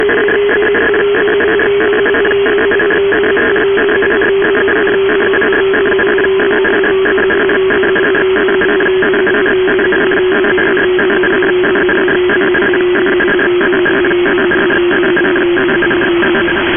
TransitPCM.mp3